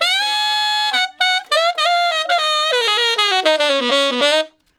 068 Ten Sax Straight (Ab) 10.wav